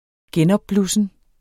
Udtale [ ˈgεnʌbˌblusən ]